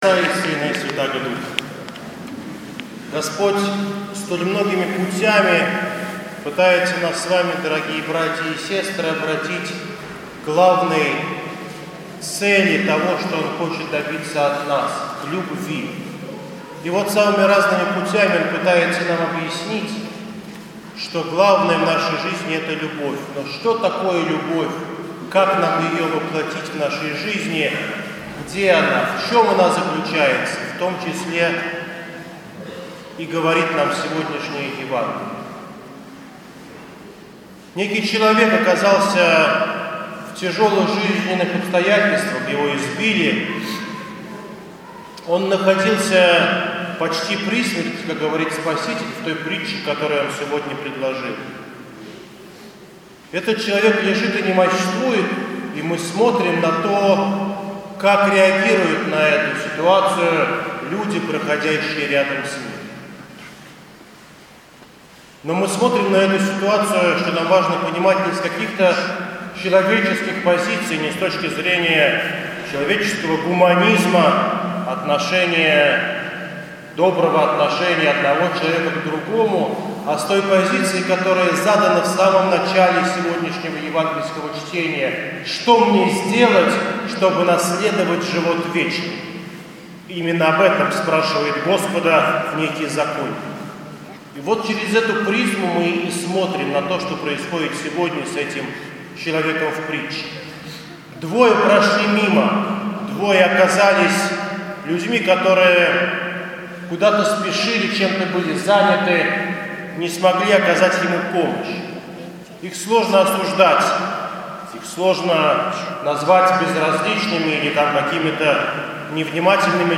Воскресная проповедь в неделю 25-ю по Пятидесятнице